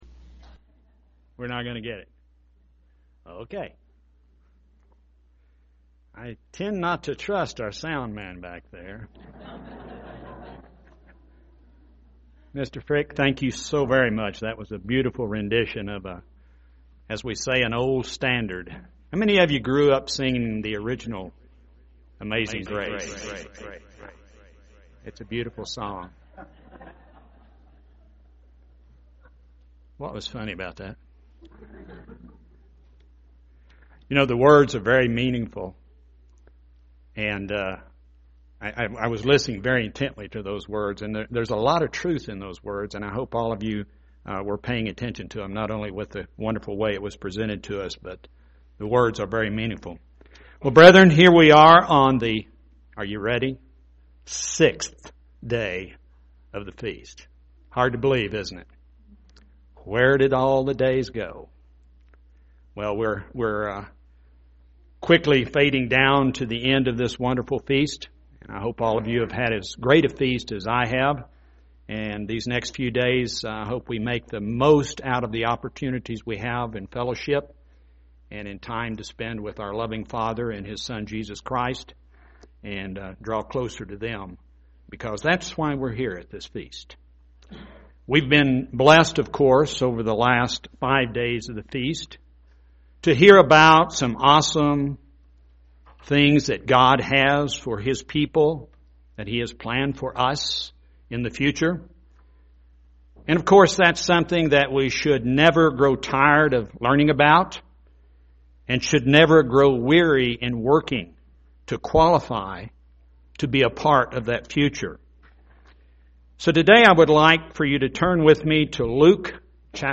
This sermon was given at the Bigfork, Montana 2014 Feast site.